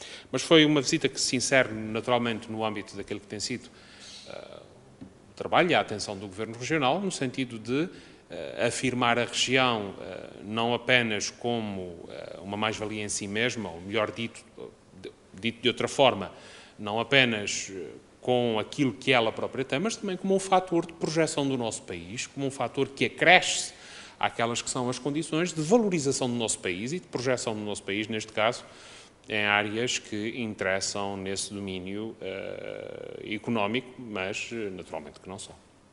“Esta visita insere-se no âmbito do trabalho do Governo dos Açores no sentido de afirmar a Região, não apenas pelo que ela própria apresenta, mas também como um fator de projeção do nosso país em áreas que interessam no domínio económico”, afirmou Vasco Cordeiro, em declarações aos jornalistas.